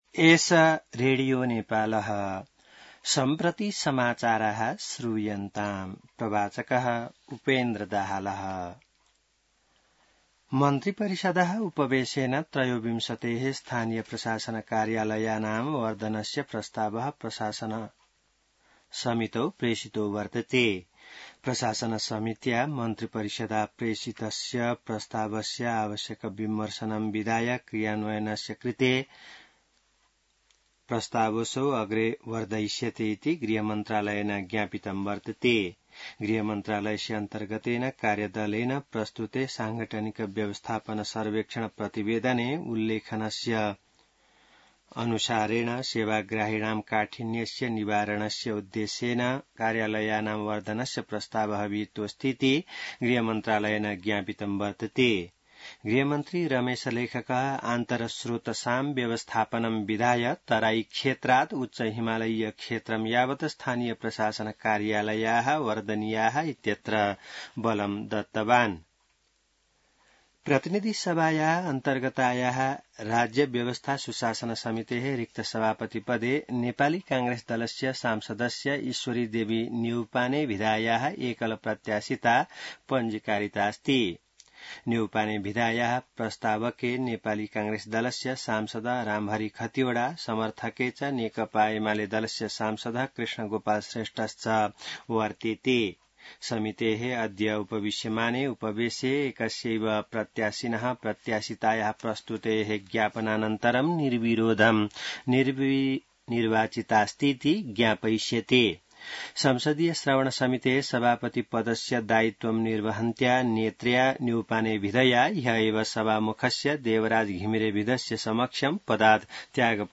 संस्कृत समाचार : ११ भदौ , २०८२